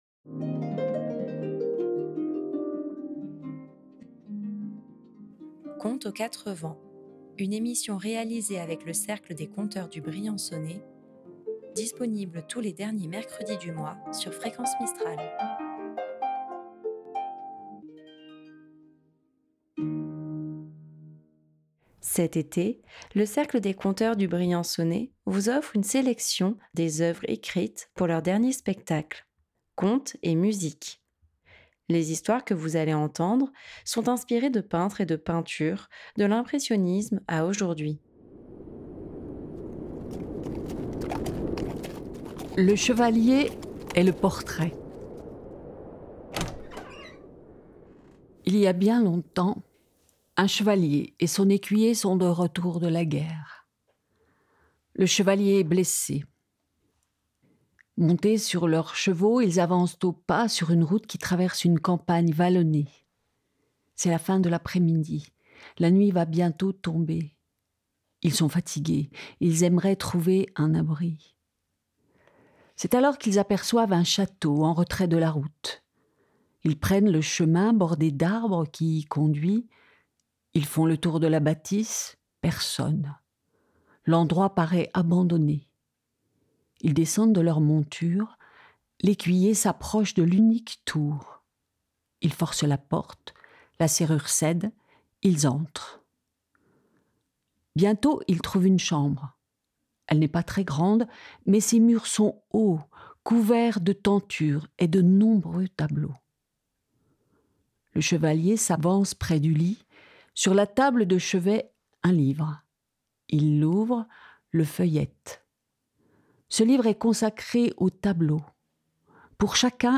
Dans cet épisode, vous entendrez des contes écrits par les membres de l'association pour leur dernier spectacle : Contes et Musique. Des histoires inspirées de tableaux, de l'impressionisme à nos jours.